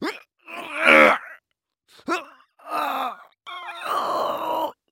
Звуки усилия
На этой странице собраны звуки усилия: стоны, напряжение, дыхание при физической нагрузке.